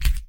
KeypressSpacebar.ogg